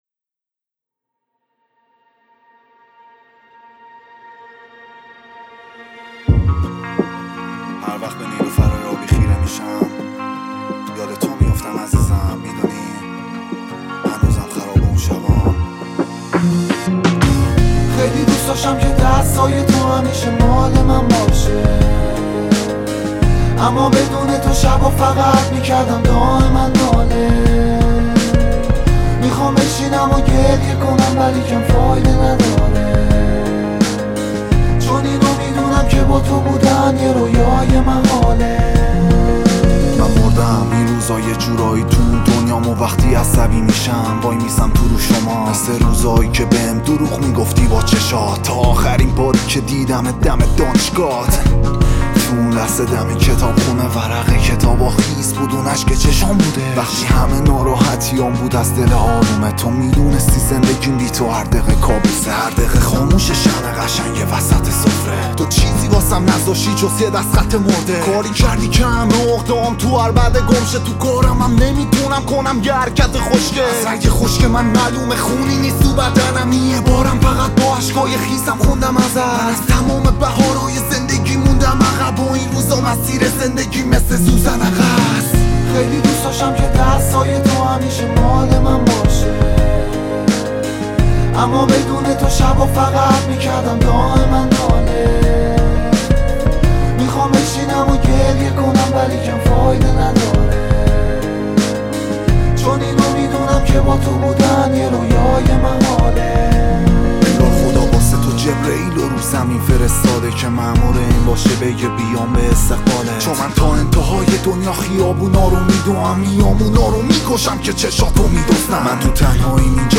فوق العاده احساسی و دلنشین